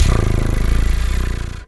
pgs/Assets/Audio/Animal_Impersonations/cat_2_purr_02.wav at master
cat_2_purr_02.wav